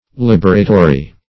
Search Result for " liberatory" : The Collaborative International Dictionary of English v.0.48: Liberatory \Lib"er*a*to*ry\ (-[.a]*t[-o]*r[y^]), a. Tending, or serving, to liberate.
liberatory.mp3